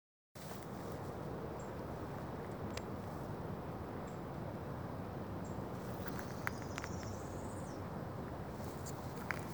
Tropical Parula (Setophaga pitiayumi)
Location or protected area: Reserva Ecológica Costanera Sur (RECS)
Condition: Wild
Certainty: Recorded vocal